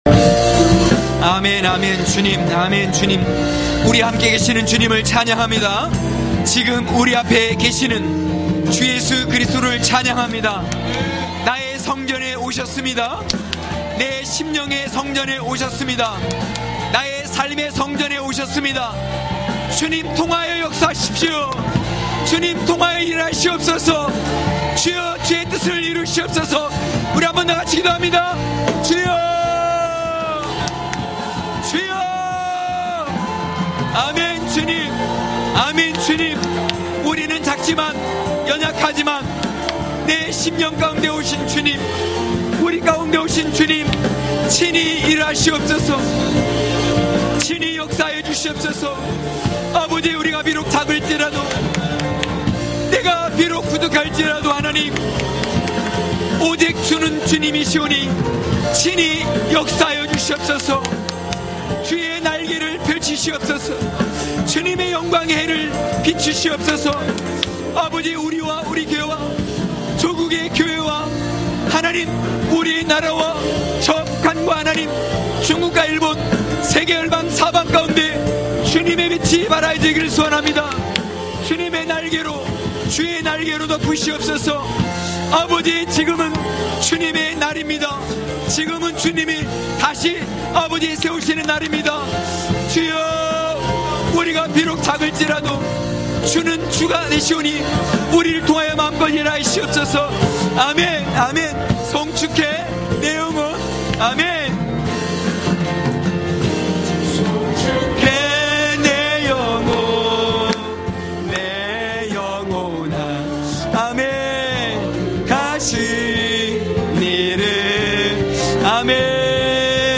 강해설교 - 4.말씀을 마음에 두고..(느2장11~20절).mp3